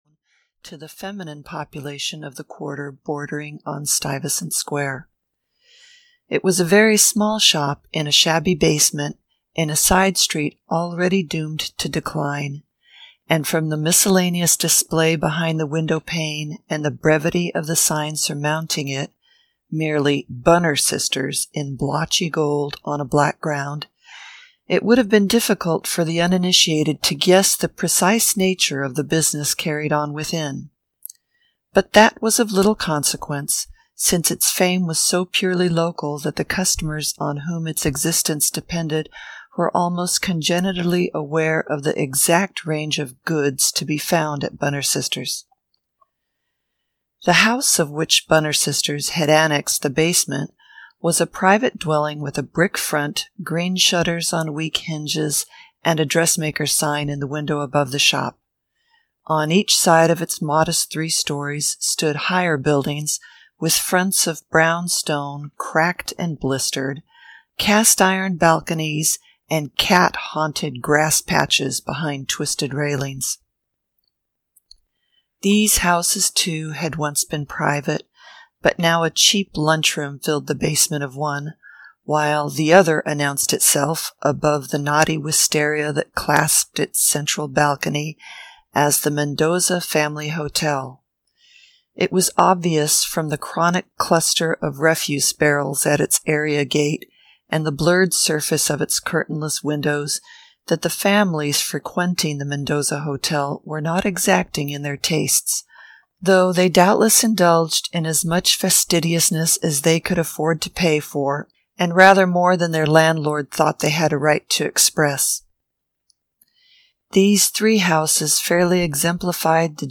Bunner Sisters (EN) audiokniha
Ukázka z knihy